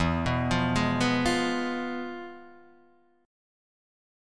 Fdim/E chord